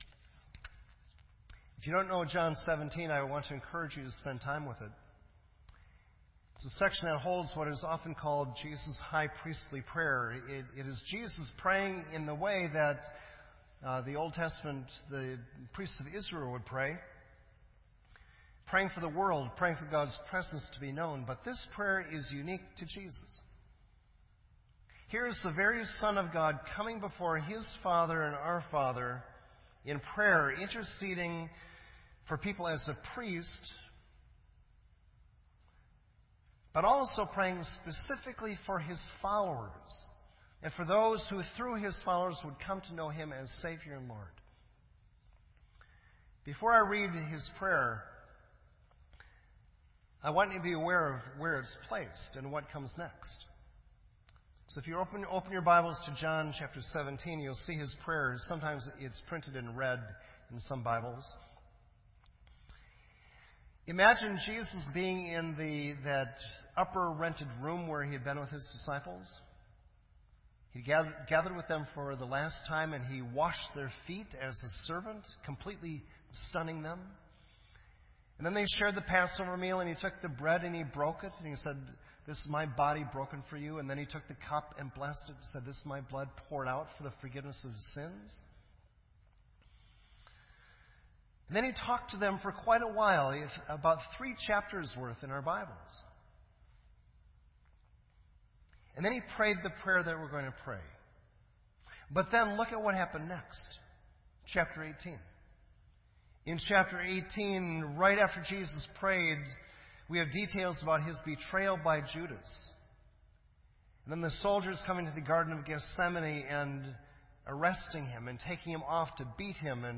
This entry was posted in Sermon Audio on February 26